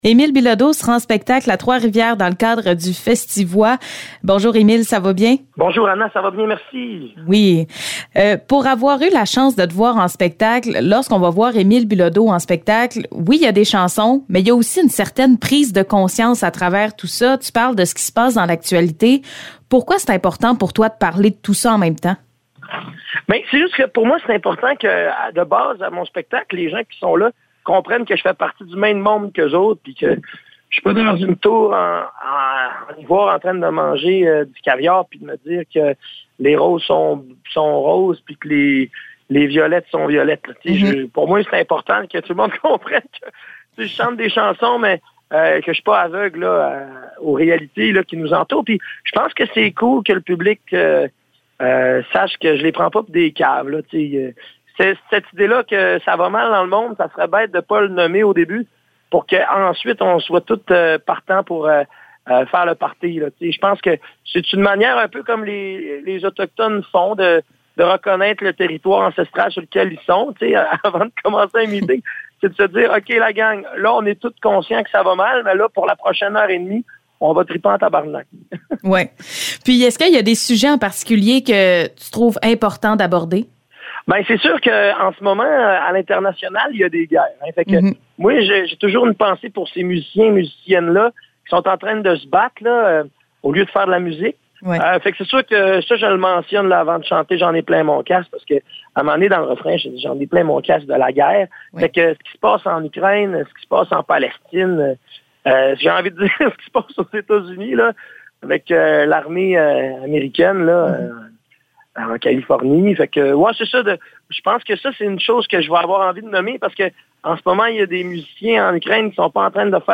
Entrevue avec Émile Bilodeau